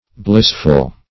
Blissful \Bliss"ful\, a.